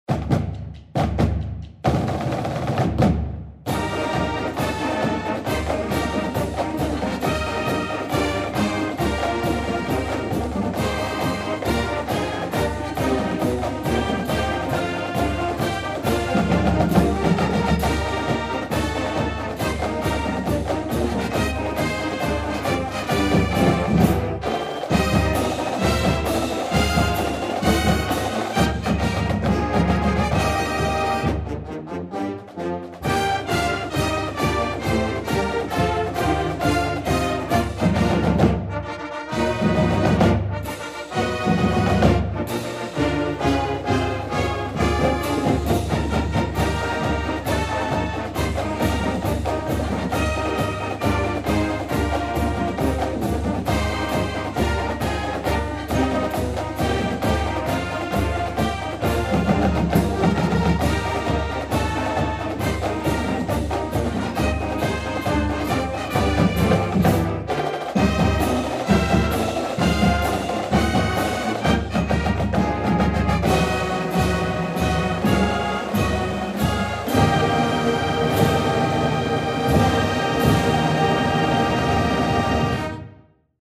Uconn_fight_song.mp3